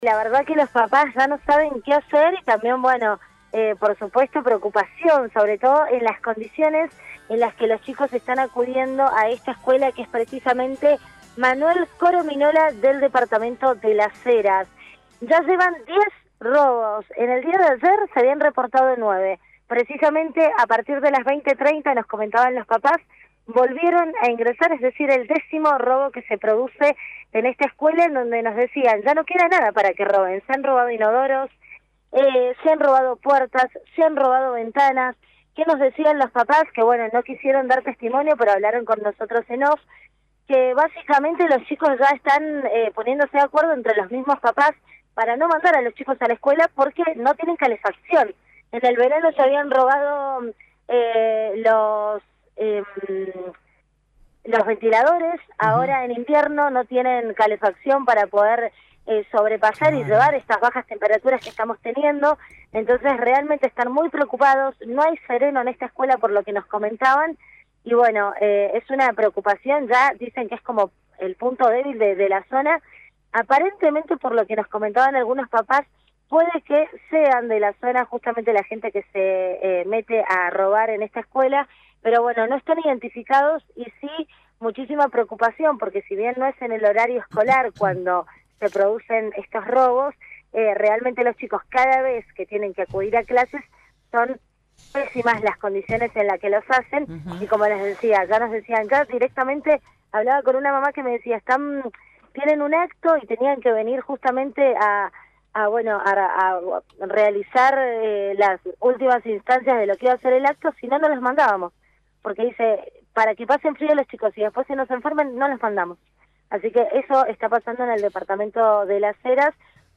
LVDiez - Radio de Cuyo - Móvil de LVDiez- robo número 10 en la Esc. Manuel Corominola de Las Heras